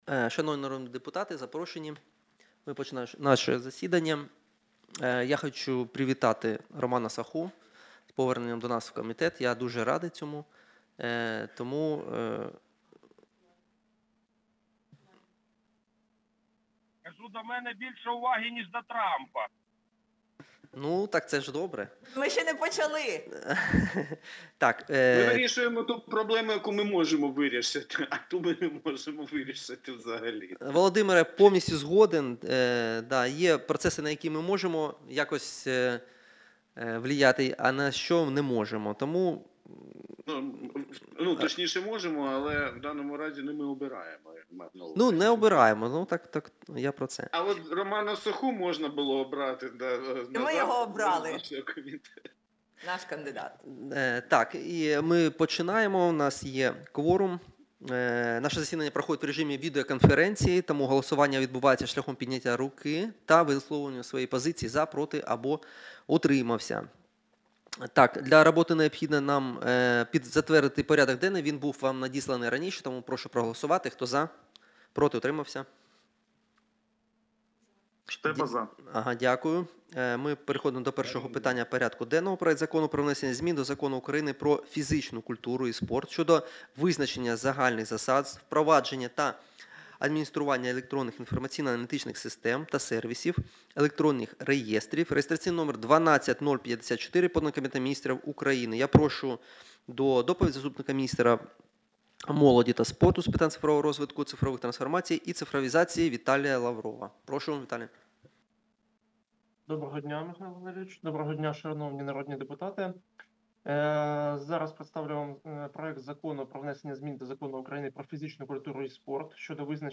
Аудіозапис засідання Комітету від 06.11.2024